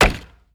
Chopping wood 1.wav